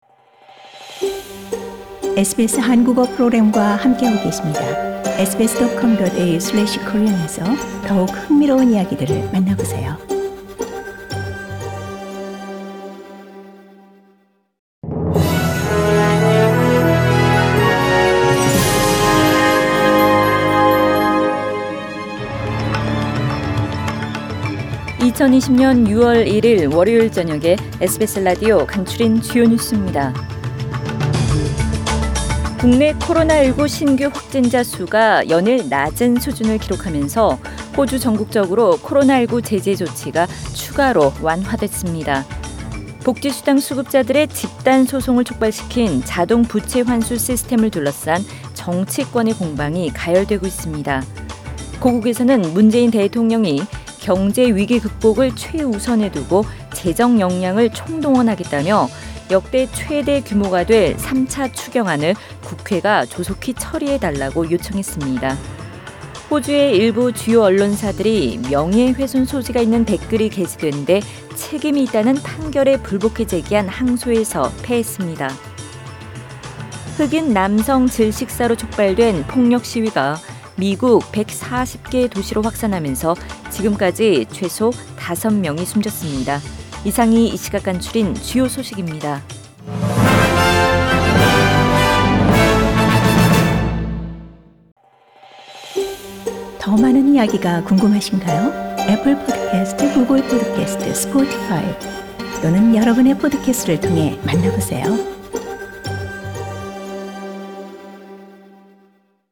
2020년 6월 1일 월요일 저녁의 SBS Radio 한국어 뉴스 간추린 주요 소식을 팟 캐스트를 통해 접하시기 바랍니다.